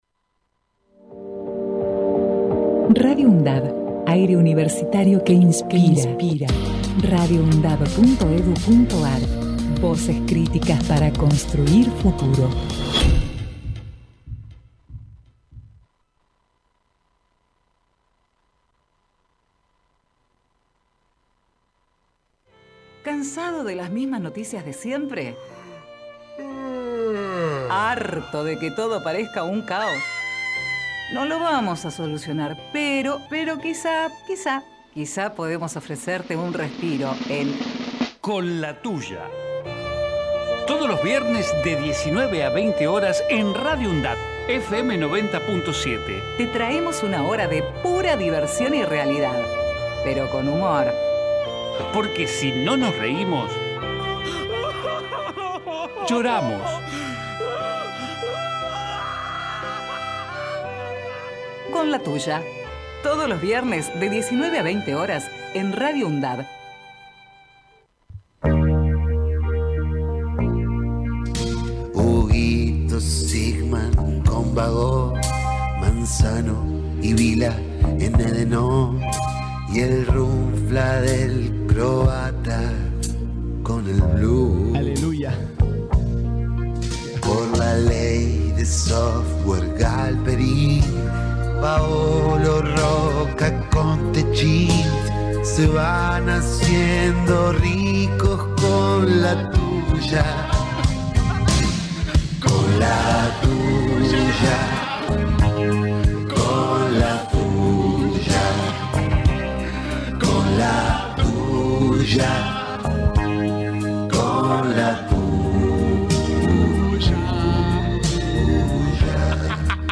Cuenta también con una columna itinerante, con entrevistas e invitados especiales que serán parte de este programa que sale los viernes de 19 a 20.